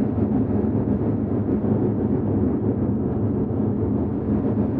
SS_CreepVoxLoopA-12.wav